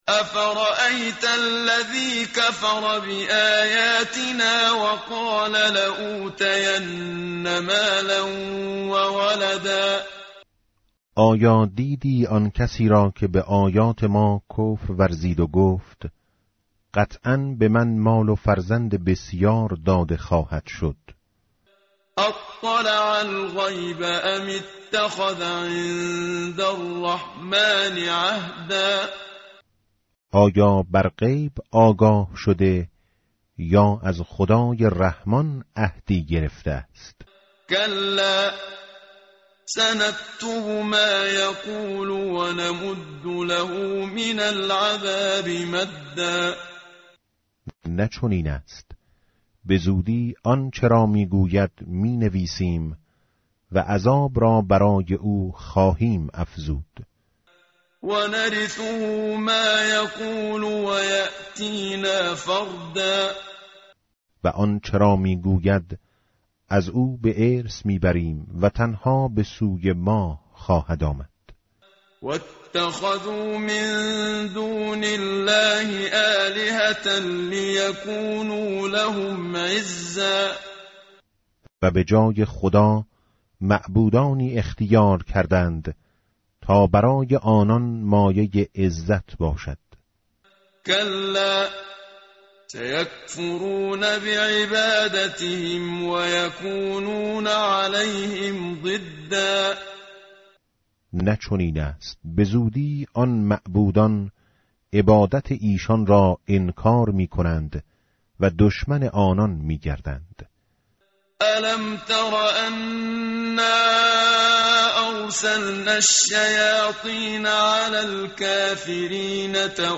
tartil_menshavi va tarjome_Page_311.mp3